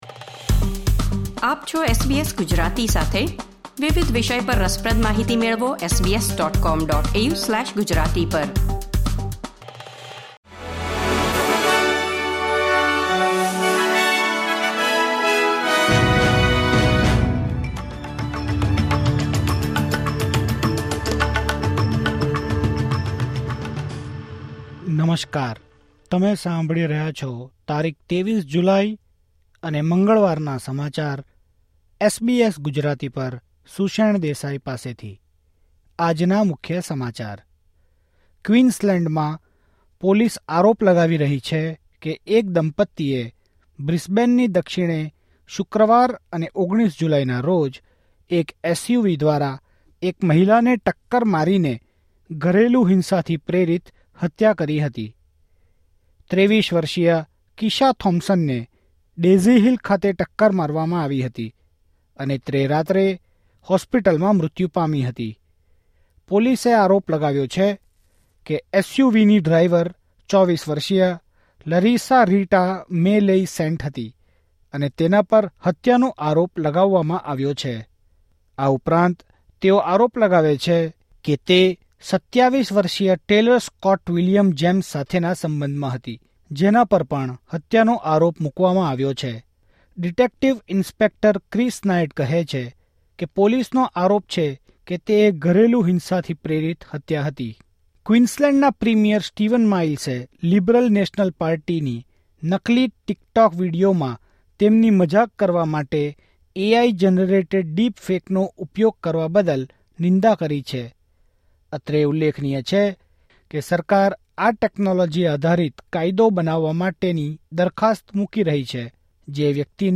SBS Gujarati News Bulletin 23 July 2024